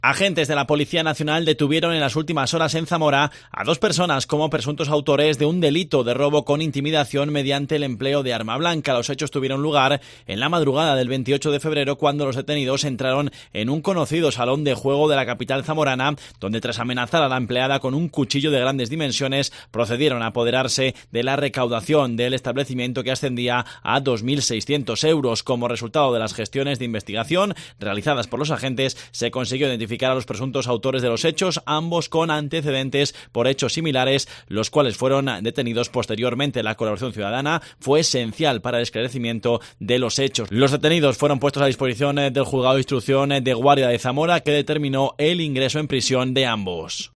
Crónica robo en un establecimiento de Zamora a punta de cuchillo